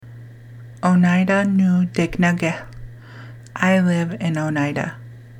Click below each question and answer to hear the Oneida and English pronunciations.